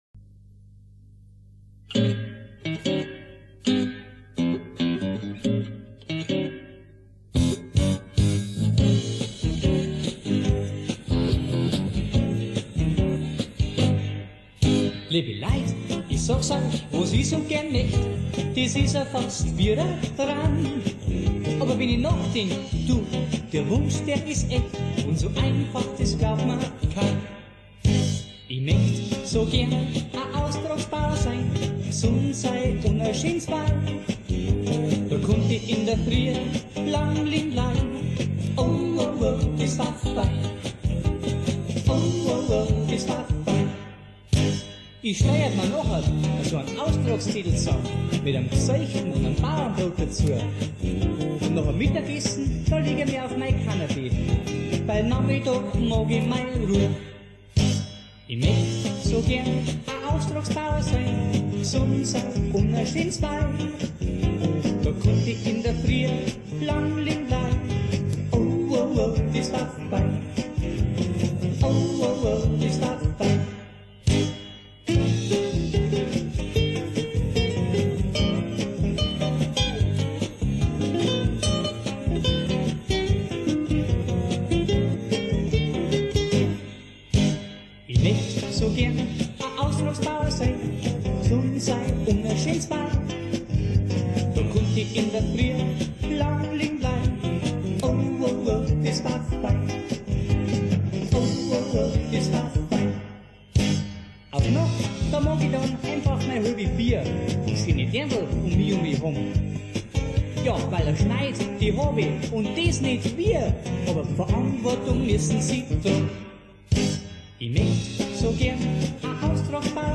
Gesang, Gitarre